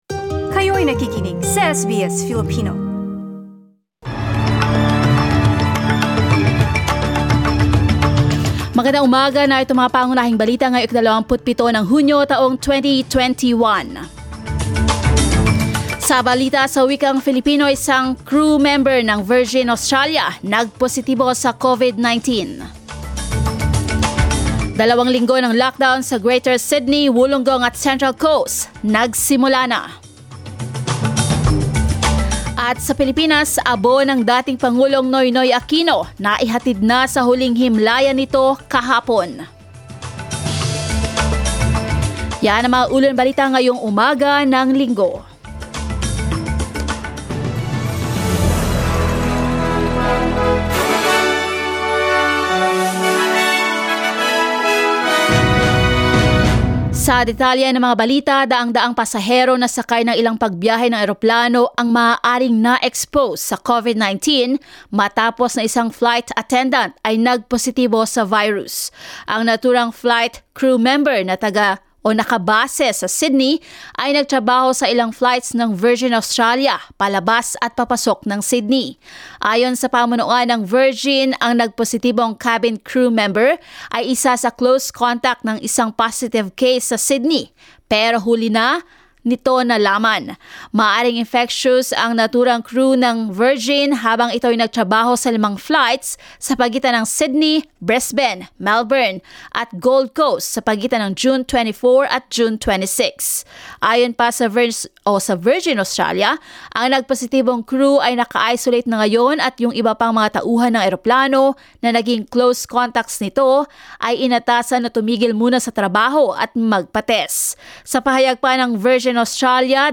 SBS News in Filipino, Sunday 27 June